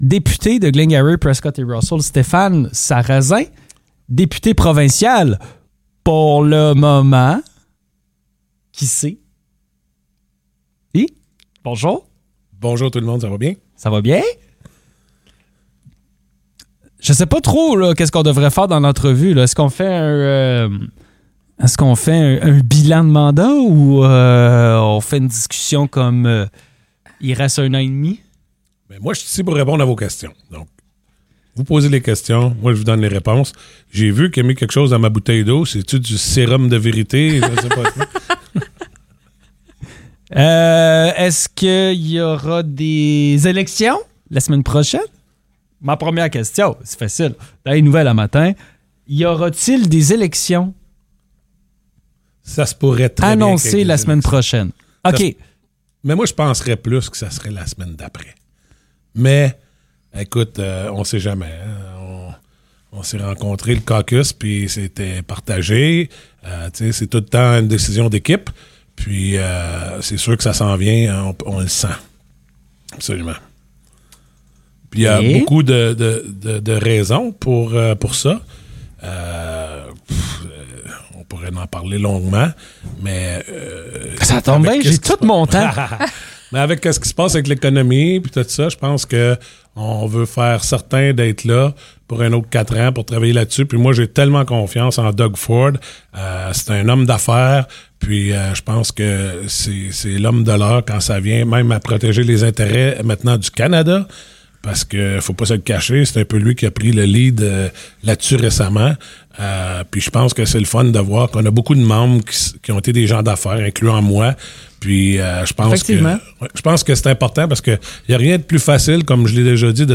Le député provincial de Glengarry-Prescott-Russell, Stéphane Sarrazin, est venu passer une heure avec nous en studio.